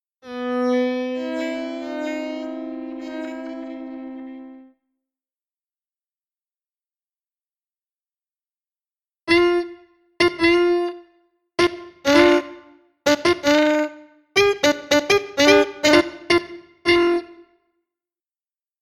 A (low quality) piano does not sound like a
piano anymore….